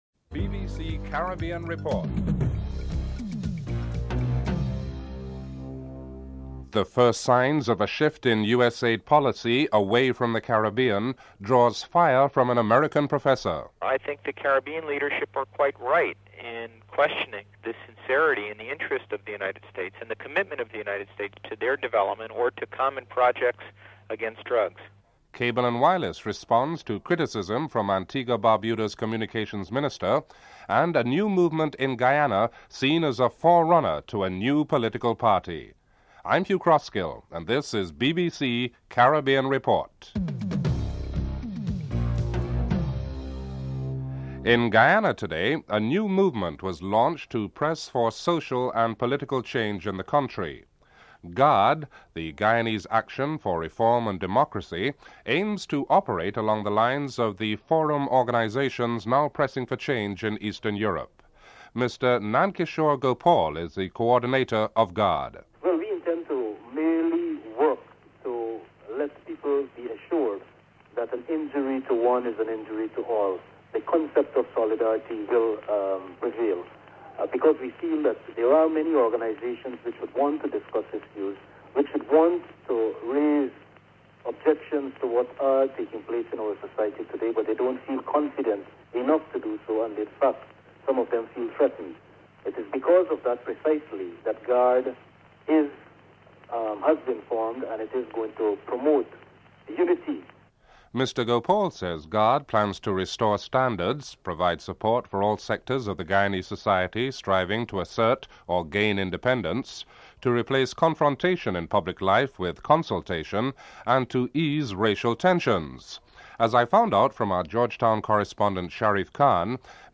1. Headlines (00:00-00:44)
Telephone interview
Brian Redhead interviews the English captain, Mike Gatting and the report also includes comments from Clive Lloyd, manager of the West Indies cricket team (11:12-14:52)